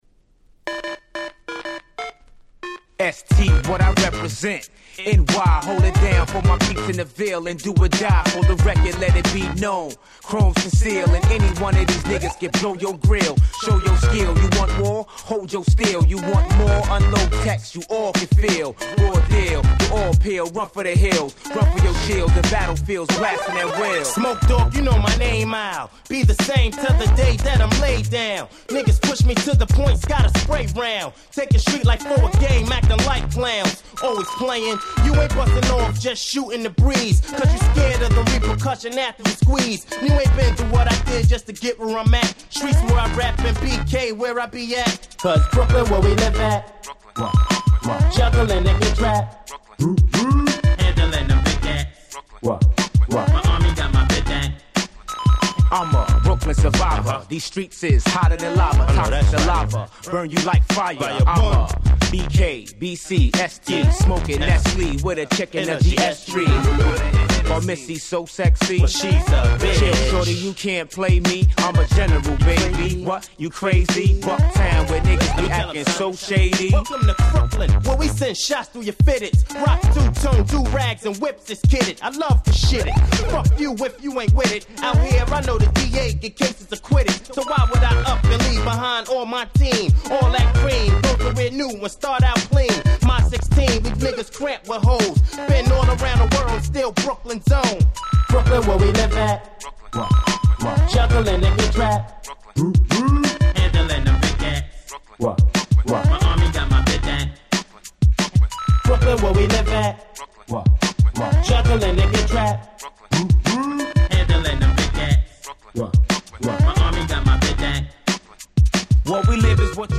Hip Hop Classics !!
大変キャッチーな1曲ですが、きっと決してふざけている訳ではありません！(笑)